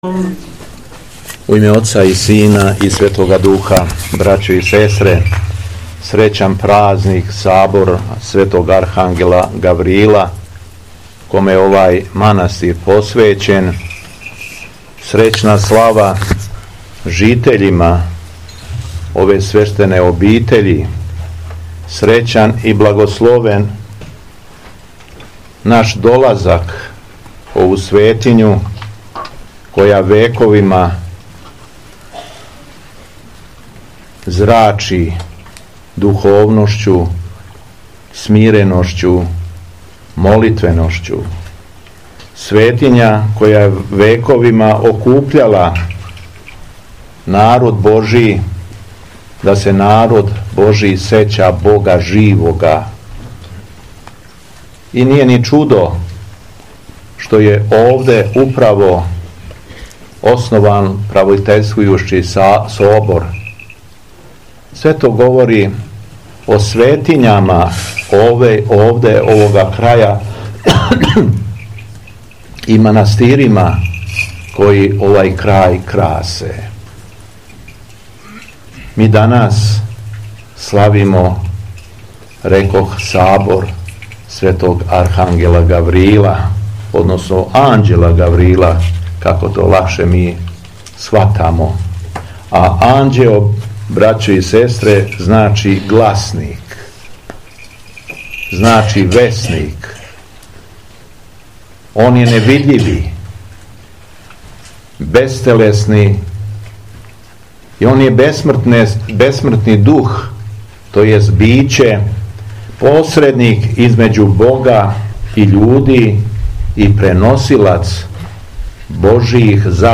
У суботу, 26. јула 2025. године, када наша Света Црква молитвено прославља Светог Архангела Гаврила, Његово Високопреосвештенство Митрополит шумадијски Господин Јован служио је Свету архијерејску Литургију у манастиру Вољавча, а повод је био храмовна слава ове свете обитељи.
Беседа Његовог Високопреосвештенства Митрополита шумадијског г. Јована